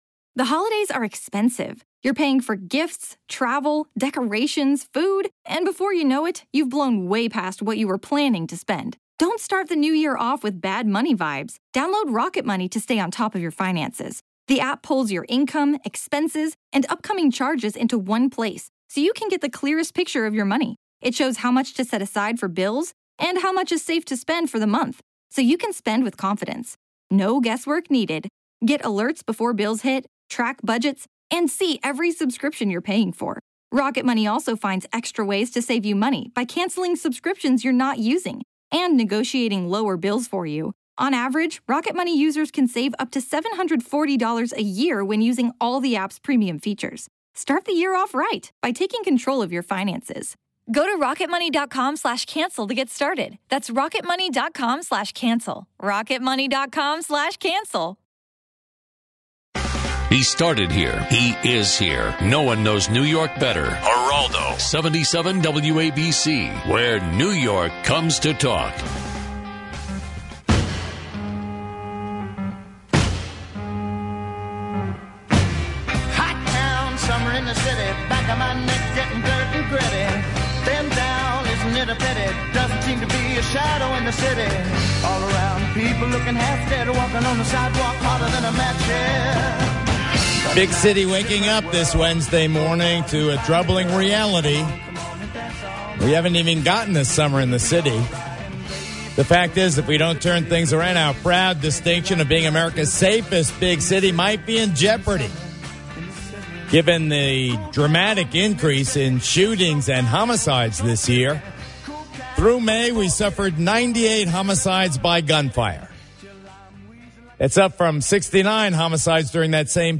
Geraldo Rivera talks about issues New Yorkers care about.